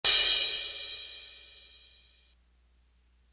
Living The Life Crash.wav